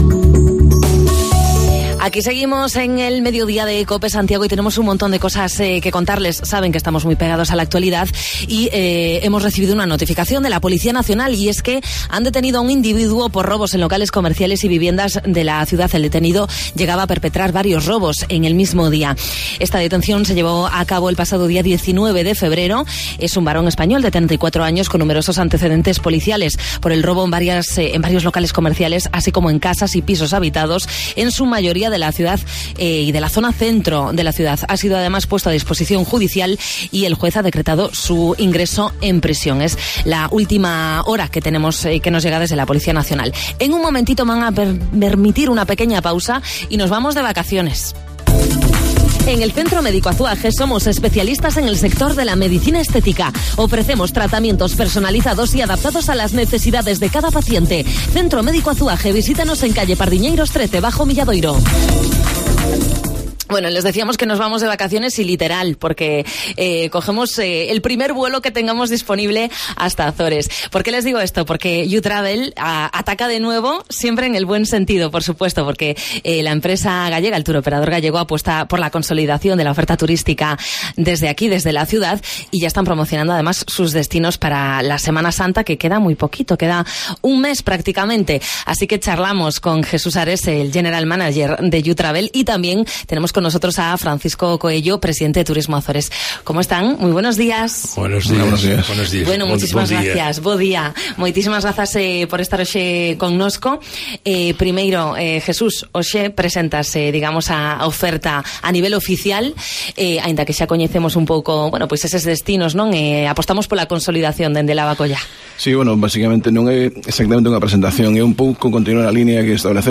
Entrevista YuTravel